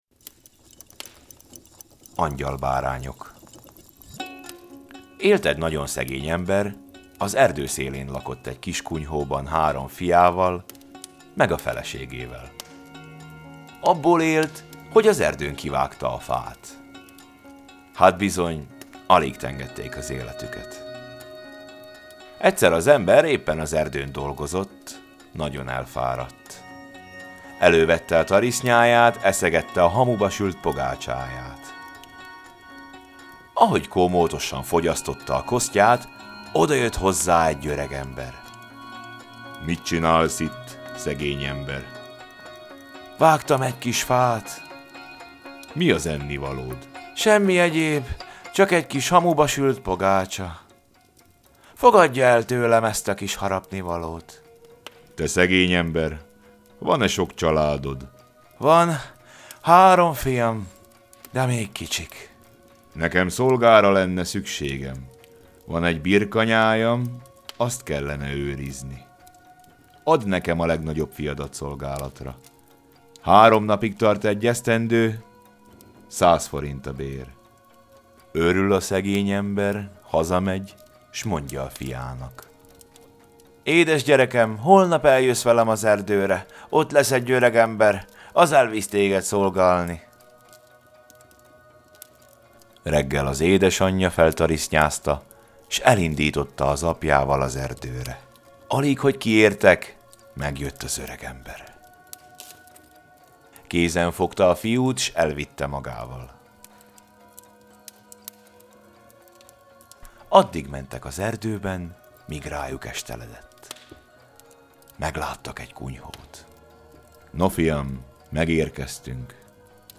Apparat: Sweet Unrest